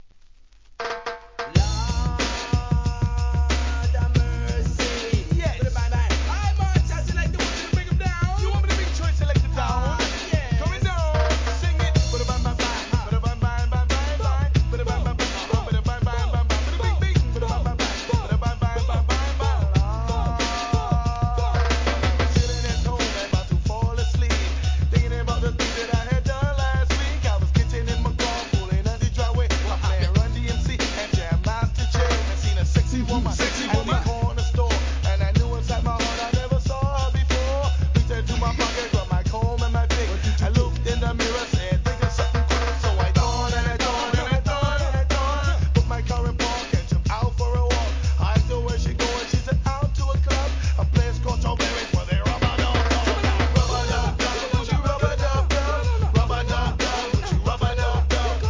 HIP HOP/R&B
イケイケのマイアミ・ベースからラガHIP HOP、甘〜いソウル・ナンバーまで!!